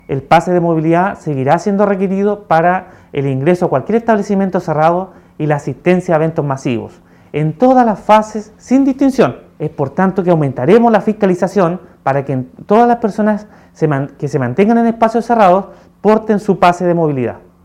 Por parte de la autoridad, el seremi de Salud, Andrés Cuyul, afirmó que se intensificarán las fiscalizaciones tanto al Pase de Movilidad como al uso de mascarillas.
cuna-andres-cuyul.mp3